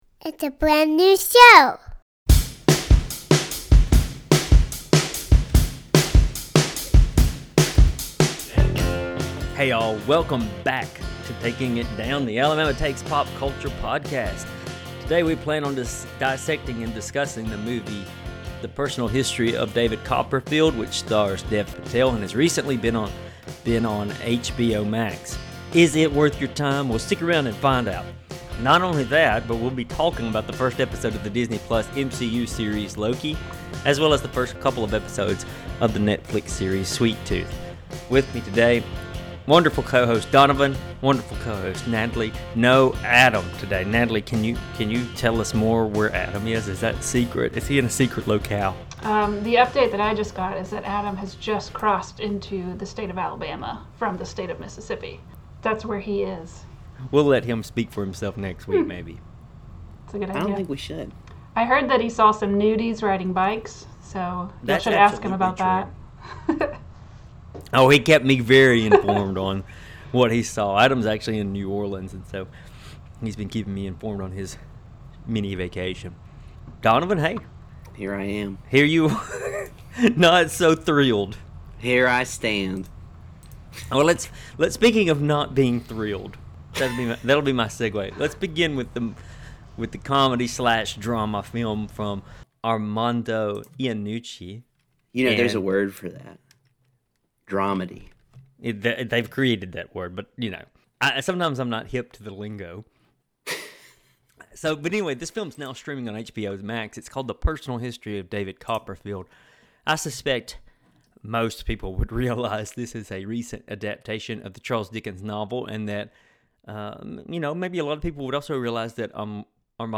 If you love horrible Owen Wilson impressions as well as disparagements about kids who act too much like adults, you're in a lot of luck this week!